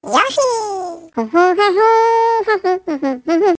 One of Yoshi's voice clips in Mario Kart 7